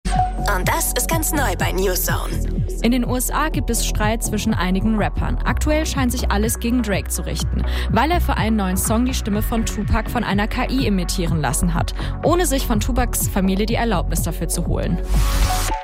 Jetzt hat er damit ohne Erlaubnis Tupacs Stimme imitiert.